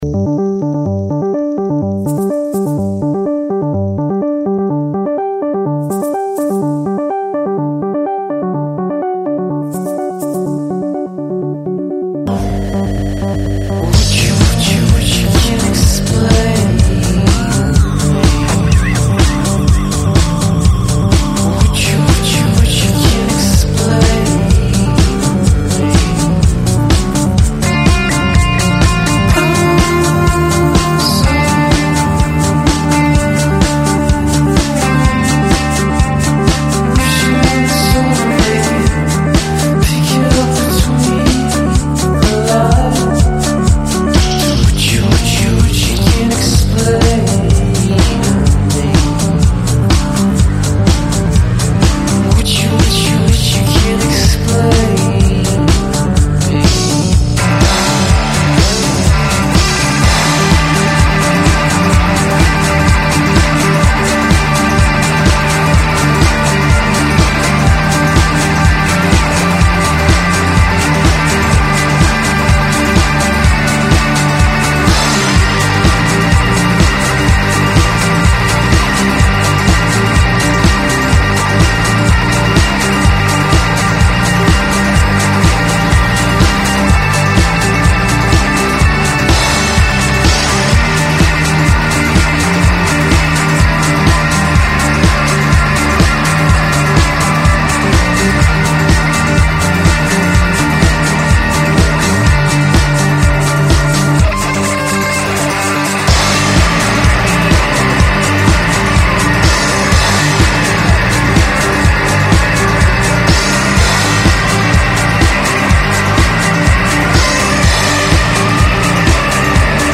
• Качество: 128, Stereo
Electronic
нарезка сделана из полной версии песни